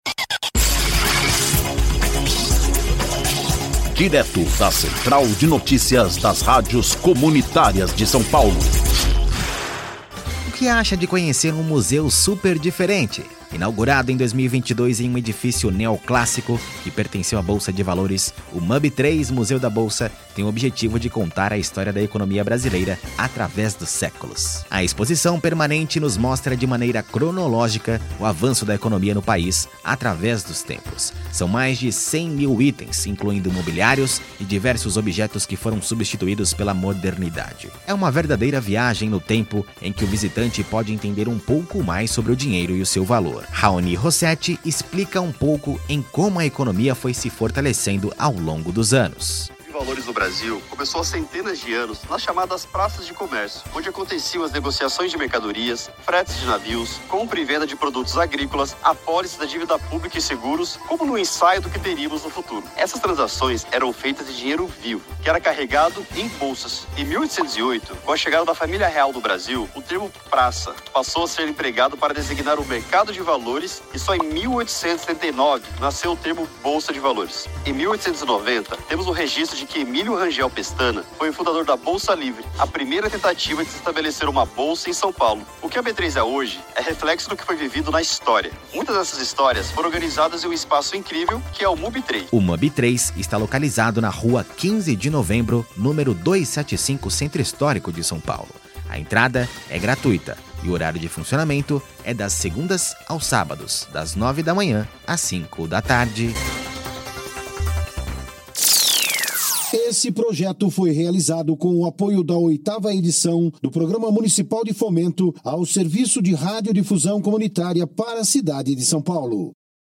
INFORMATIVO: